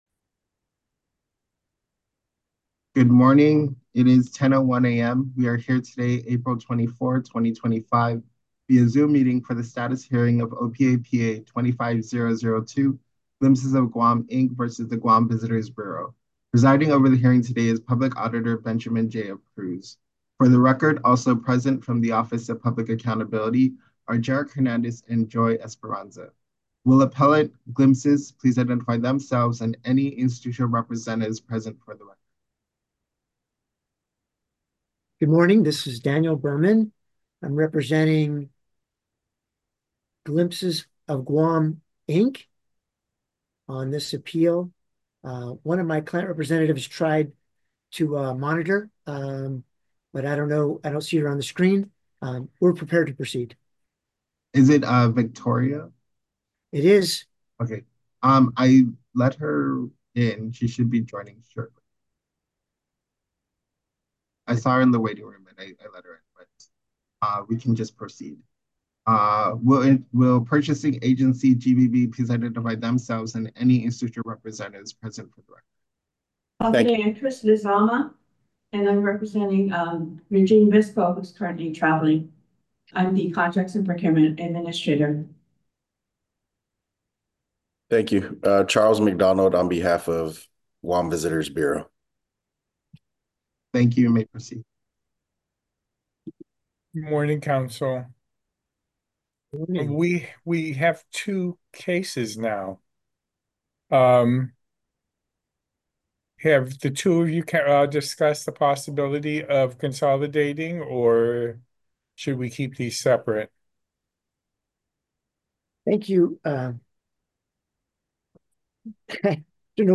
Status Hearing